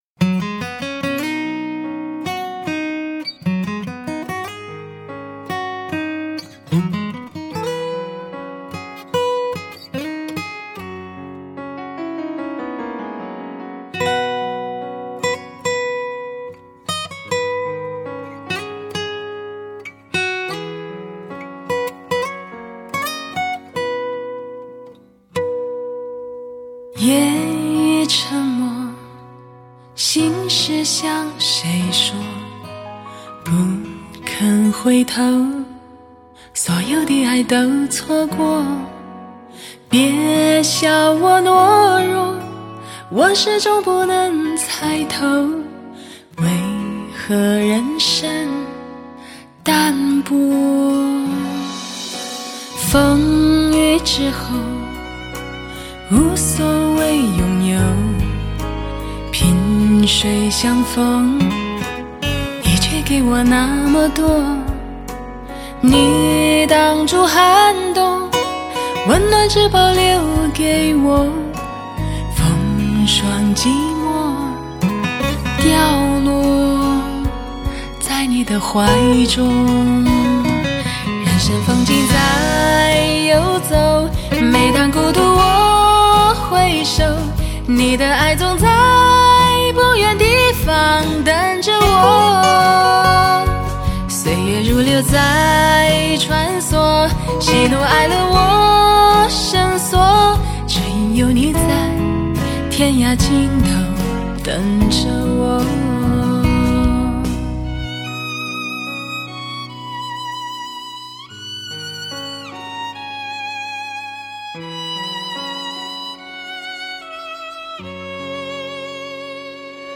丰富的音乐感 清晰 动人 自然
HIFI典范女声
唯美的弦乐、曼妙的音符，清澈的音色，带来如清晨空气般的清新与惬意。